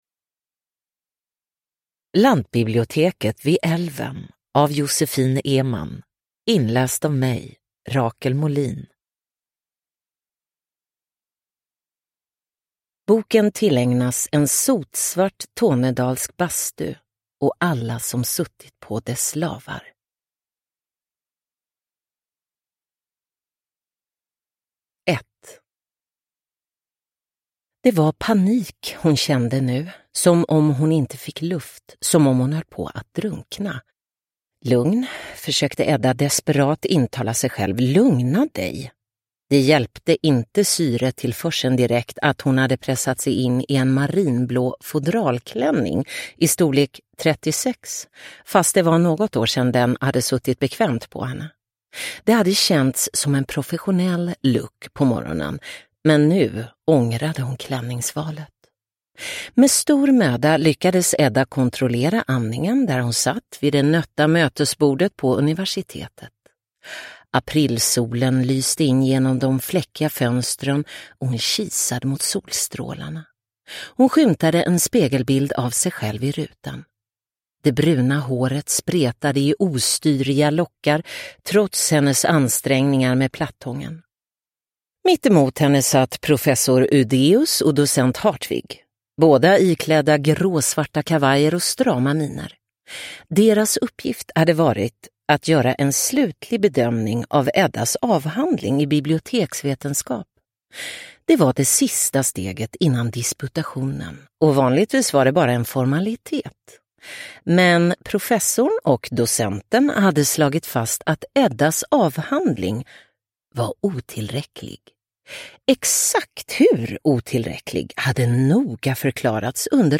Lantbiblioteket vid älven – Ljudbok – Laddas ner